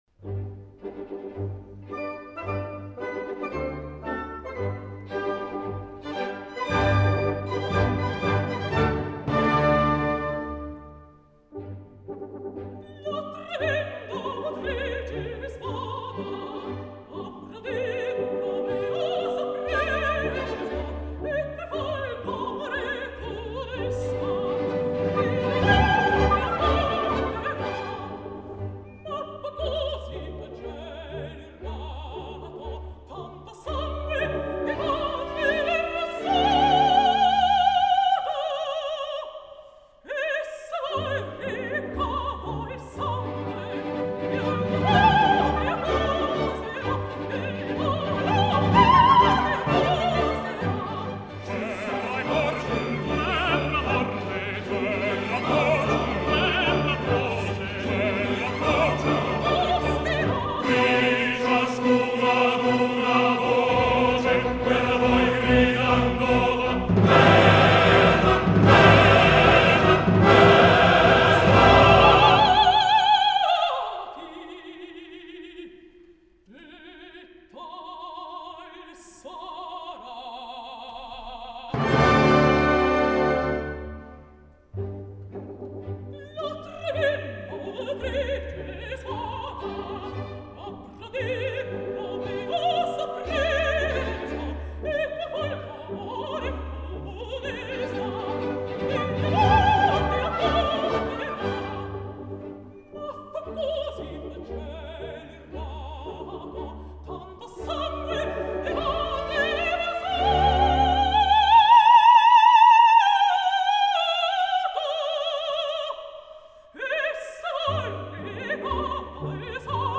Обычно партию Ромео поют меццо-сопрано, но здесь представлено и исполнение арии Ромео тенором.